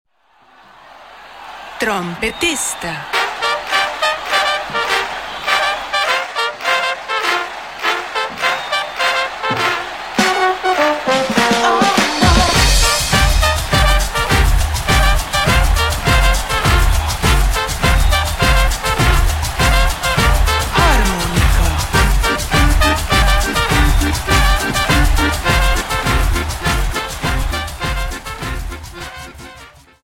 Dance: Samba 51